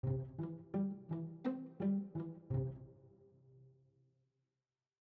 RythmGame/SimpleGame/media/chords/variation2/C.mp3 at 3ce31aa66452eaf3ba7251c27a112a138931be02